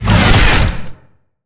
Sfx_creature_squidshark_chase_os_02.ogg